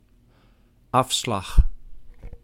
Ääntäminen
Synonyymit korting mijn veiling rabat auctie vendutie vendu verkoping zijweg Ääntäminen : IPA: [ˈɑfslɑx] Haettu sana löytyi näillä lähdekielillä: hollanti Käännös Ääninäyte Substantiivit 1. exit turn 2.